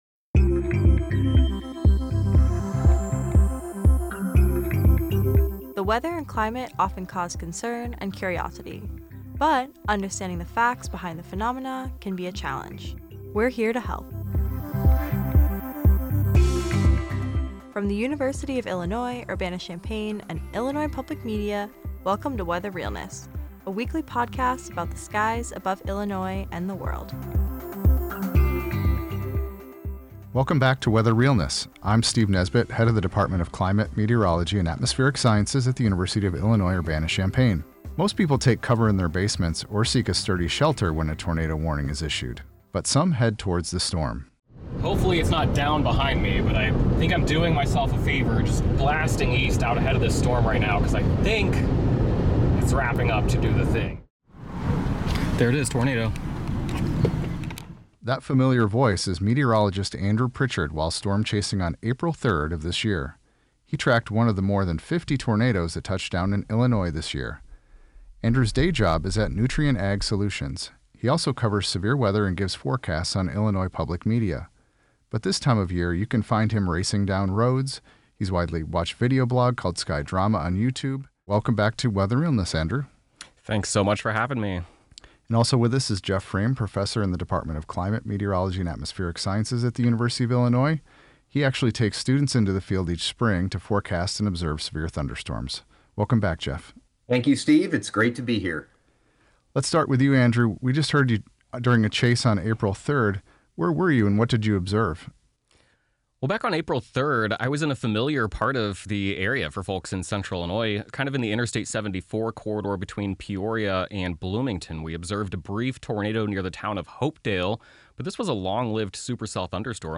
We talk to storm chasers about what it’s like to be in the path of a storm.